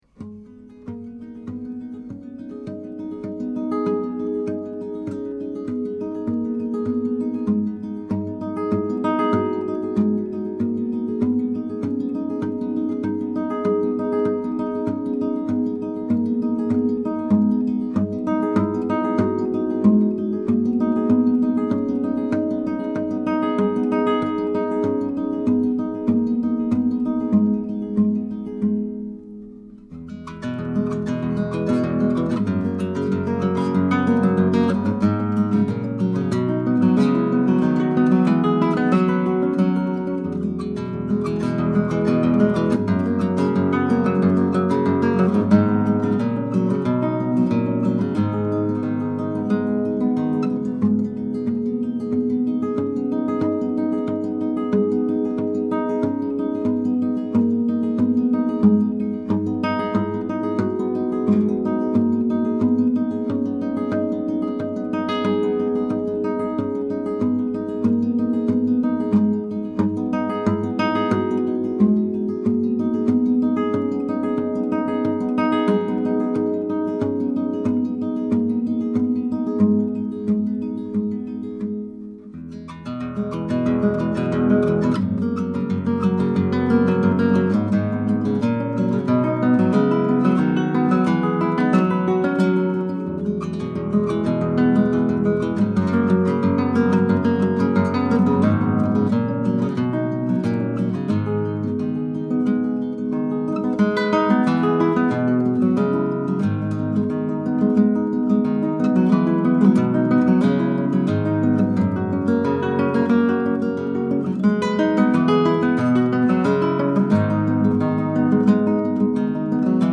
2009 Bartolex 10-String Classical Guitar
This guitar has a master-grade solid cedar top, laminated East Indian Rosewood back & Sides, ebony fretboard, and creates a beautifully-balanced Spanish sound with rounded trebles, deep basses, and excellent resonance and sympathetic sustain.
I have the guitar tuned in Romantic/Baroque tuning, a standard 10-string tuning: 1-6 is normal, and 7-10 descend step-wise: 7=D, 8=C, 9=B, 10 =A. Strings By Mail has a great selection of 10-string sets and can put together custom sets for you at a discount over individual strings.
Here are sixteen quick, 1-take MP3s of this guitar, tracked using two M7 microphones, a Wunder CM7 GT & a Neumann Gefell CMV563 into a Presonus ADL 600 preamp. This is straight, pure signal with no additional EQ or effects.